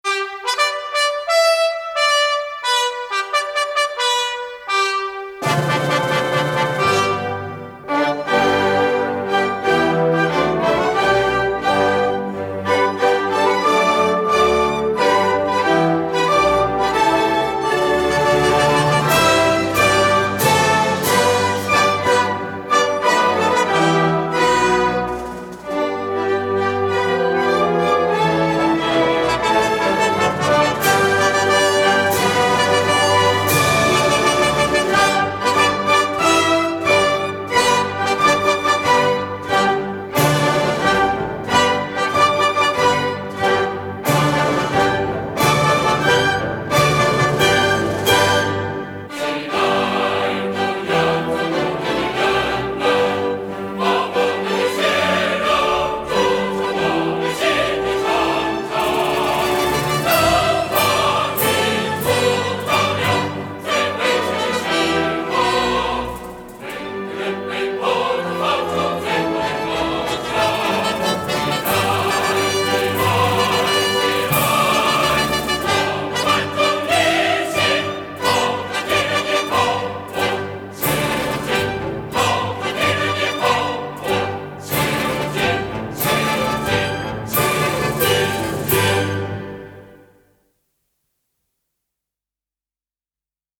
音乐类型：民乐
管弦乐合奏与齐唱